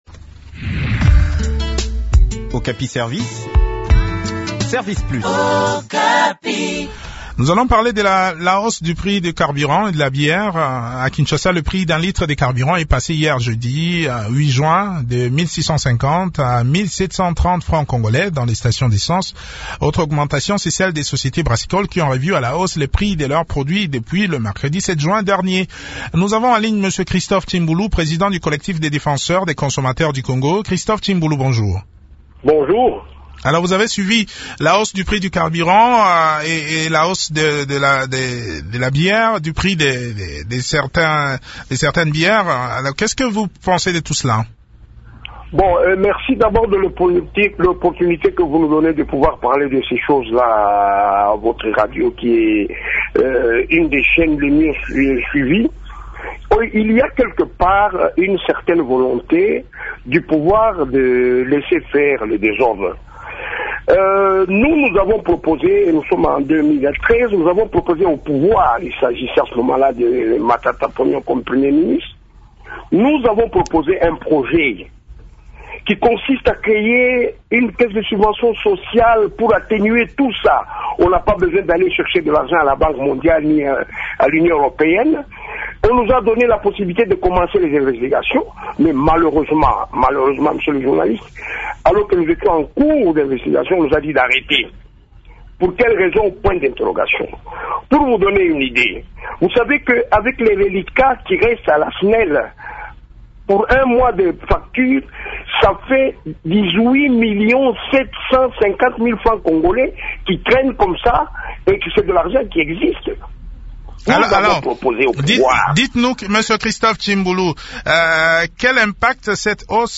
Le point de la situation sur terrain dans cet entretien